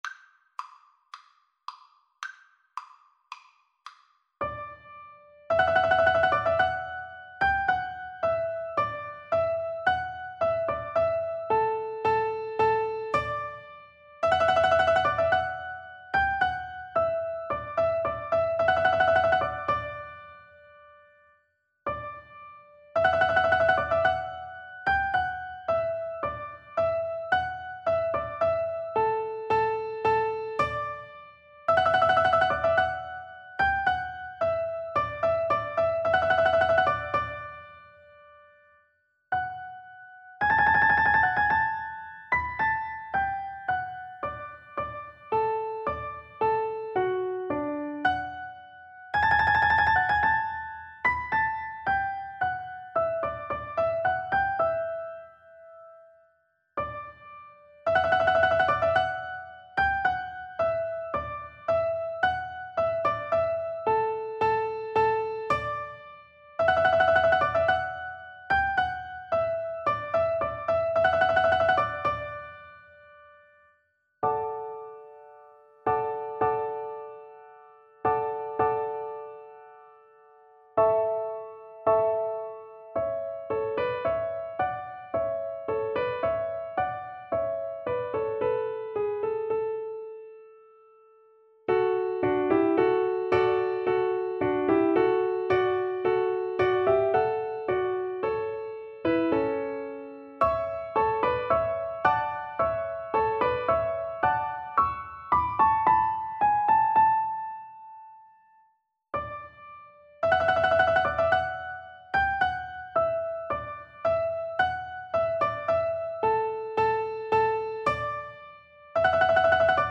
Allegro moderato =110 (View more music marked Allegro)
Classical (View more Classical Piano Duet Music)